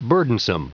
Prononciation du mot burdensome en anglais (fichier audio)
Prononciation du mot : burdensome